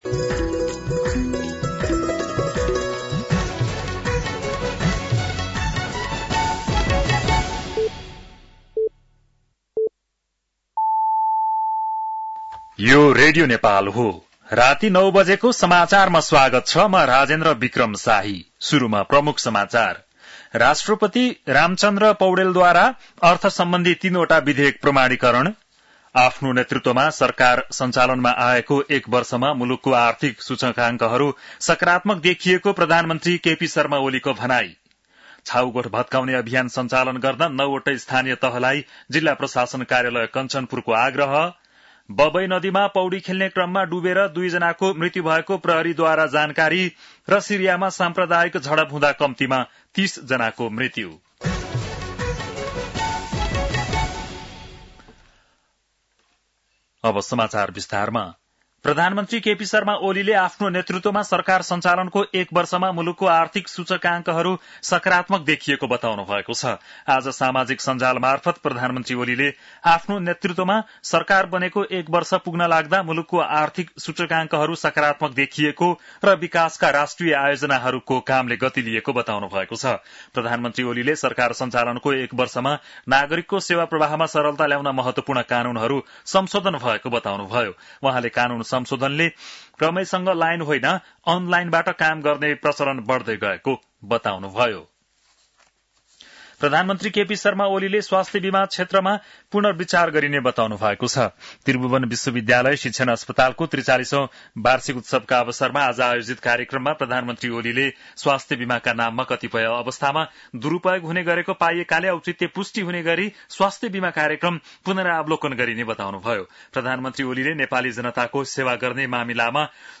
बेलुकी ९ बजेको नेपाली समाचार : ३० असार , २०८२
9-PM-Nepali-NEWS-3-30.mp3